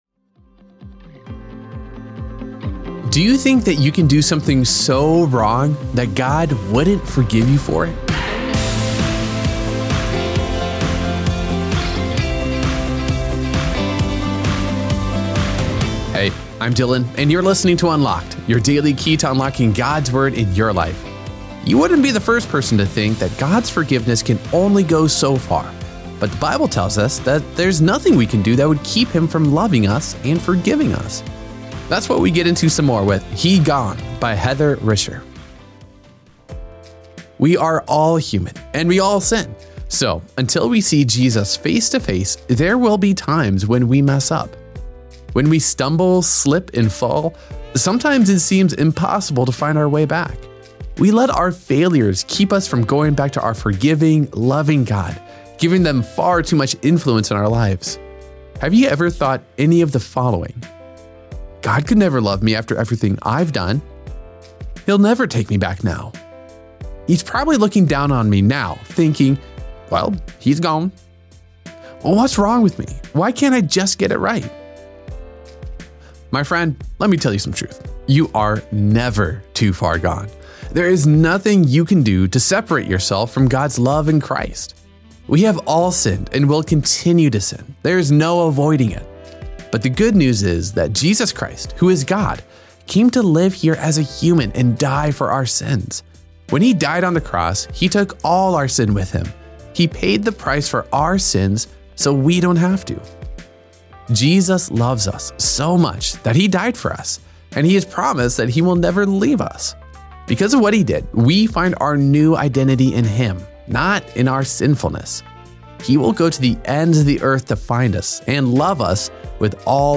Unlocked is a daily teen devotional, centered on God’s Word. Each day’s devotion—whether fiction, poetry, or essay—asks the question: How does Jesus and what He did affect today’s topic?